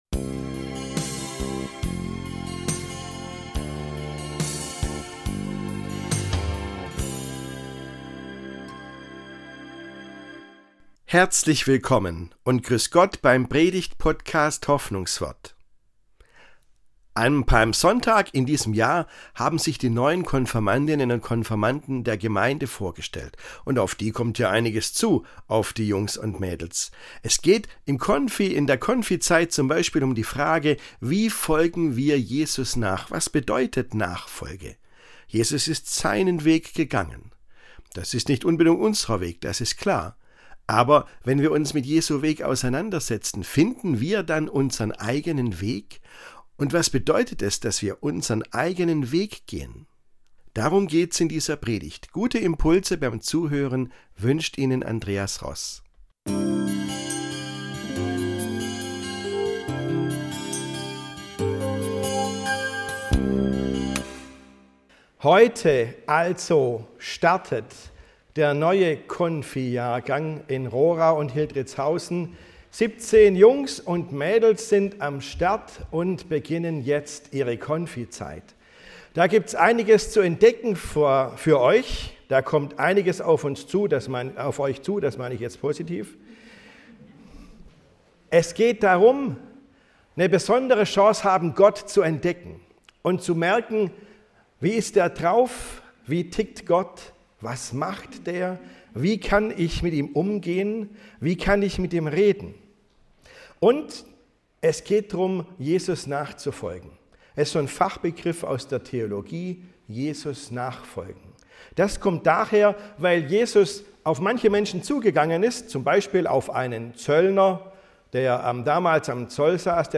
In dieser Predigt am Beginn des neuen Konfirmandenjahres geht es um die Frage: Gehst auch Du Deinen Weg?